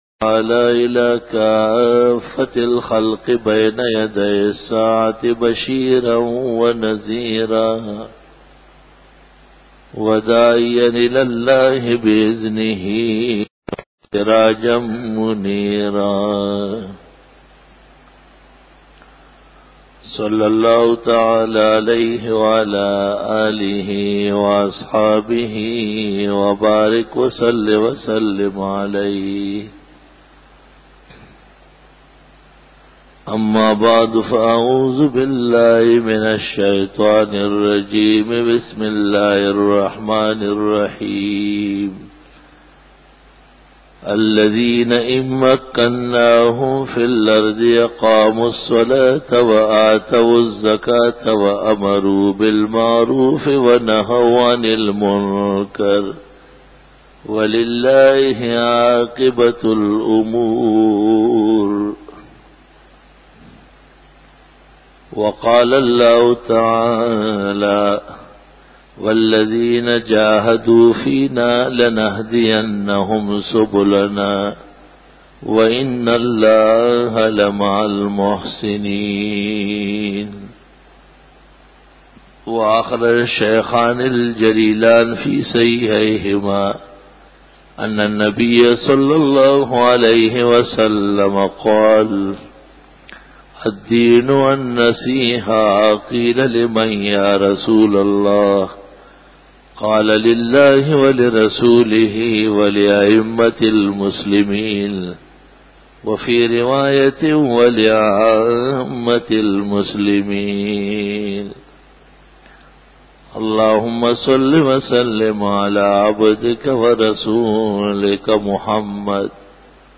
بیان جمعۃ المبارک